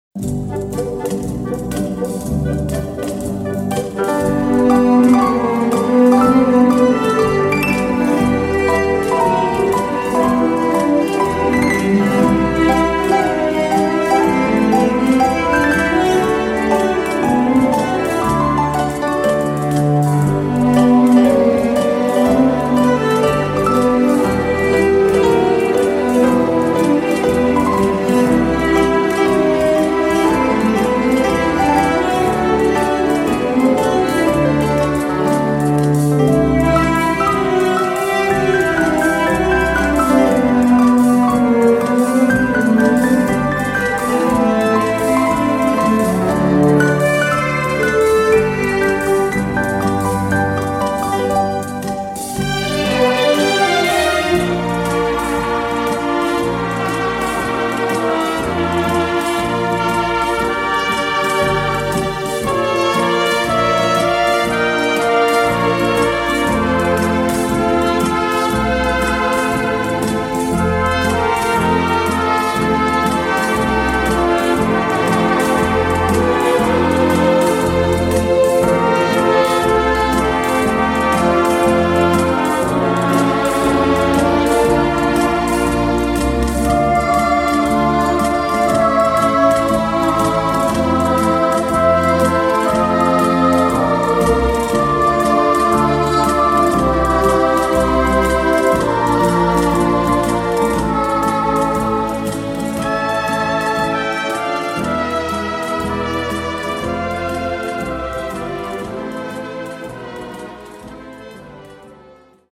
Classical Soft Melancholy
Trumpet
Cello